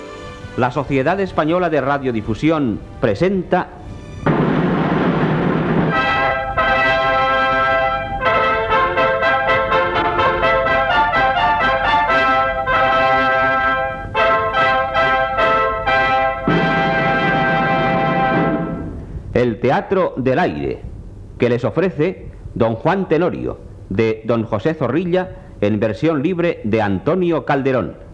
Careta del programa.